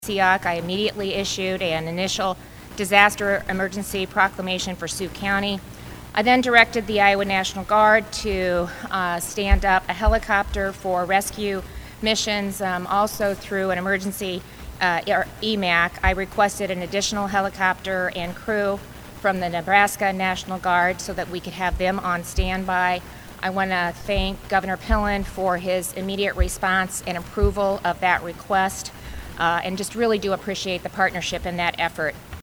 (Johnston) Iowa Governor Kim Reynolds held a press conference on Sunday at the State Emergency Operations Center in Johnston to address the ongoing flooding emergency in northwest Iowa. Reynolds stated that massive rainfall began on Friday and caused severe flooding across several counties in northwest Iowa.